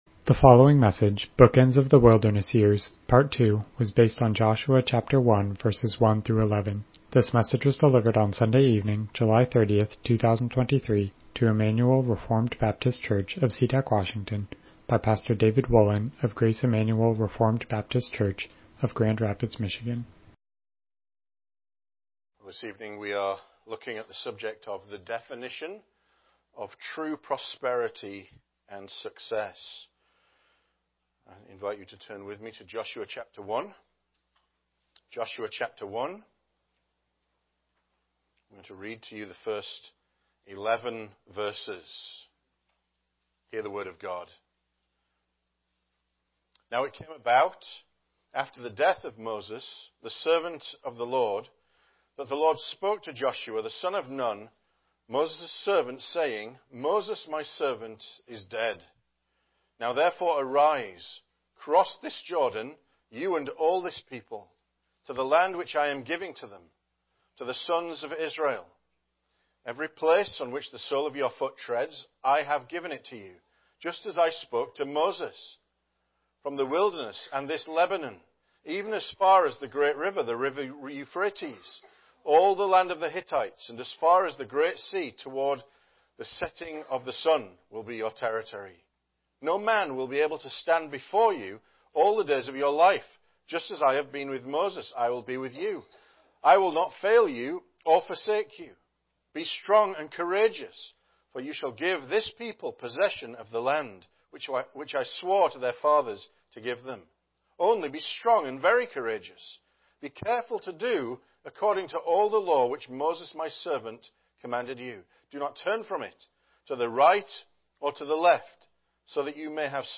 Passage: Joshua 1:1-11 Service Type: Evening Worship « Bookends of the Wilderness Years